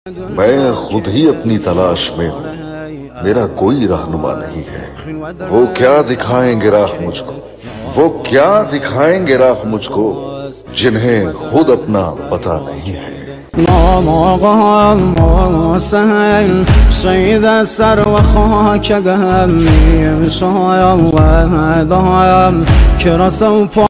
Repost ❤‍🔥|| Motivational Speech 🥀🔥 sound effects free download